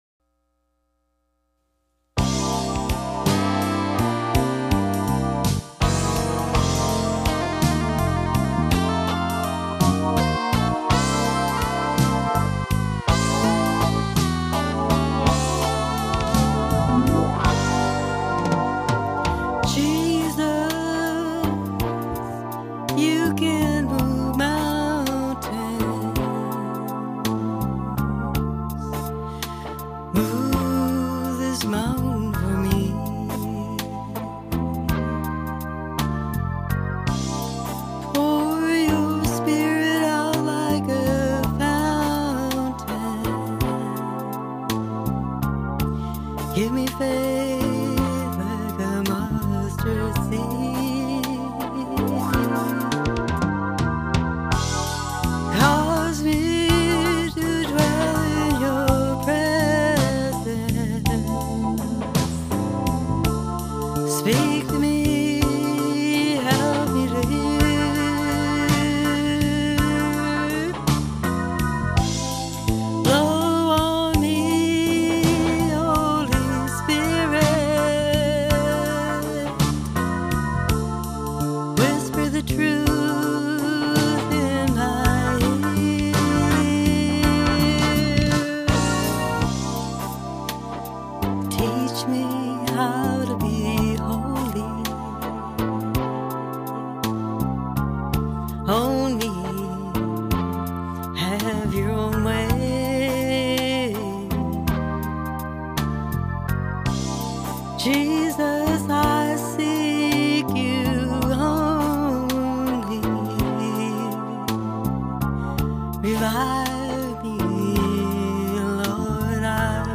Jesus-You-Can-Move-Mountains-Vocal-Trax.mp3